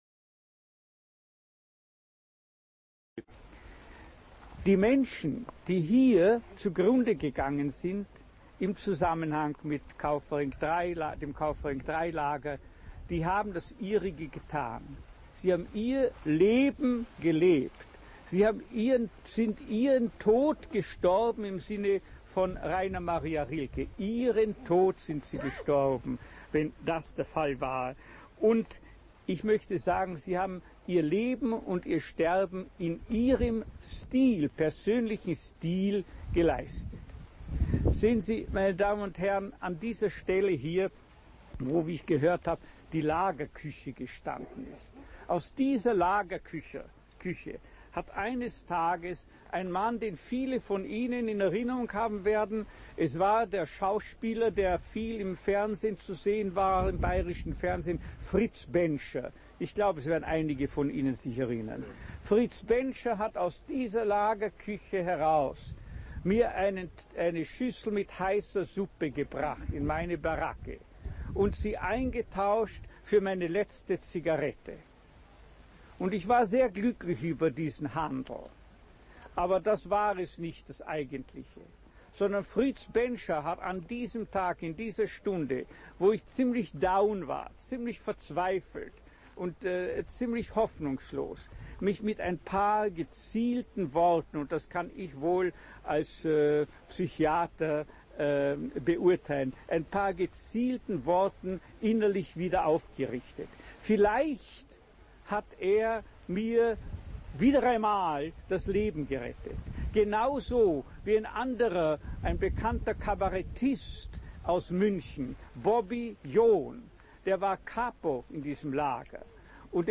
Landsberger Zeitgeschichte: Tondokument - Viktor Frankl
Viktor Frankl: Auszug aus der Rede am 11. November 1984 in Kaufering anlässlich der Einweihung des Gedenksteins auf dem ehemaligen KZ-Lager Kaufering III